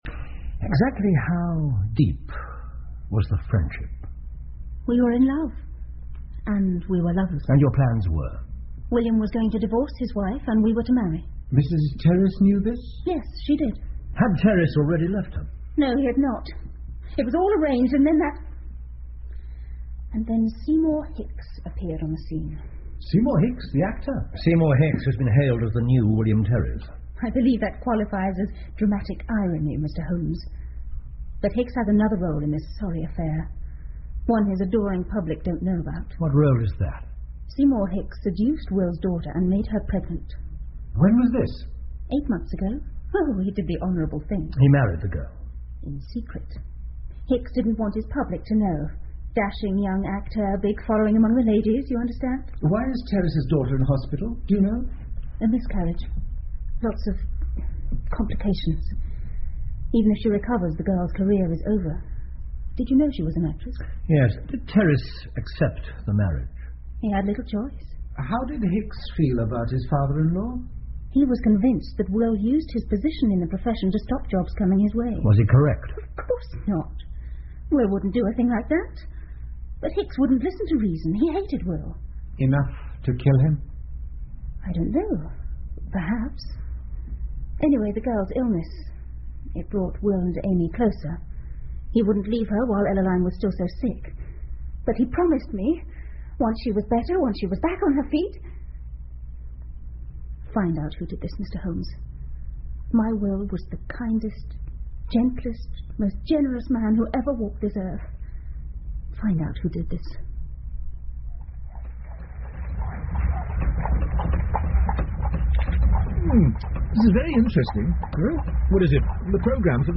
福尔摩斯广播剧 Futher Adventures-The Star Of The Adelphi 4 听力文件下载—在线英语听力室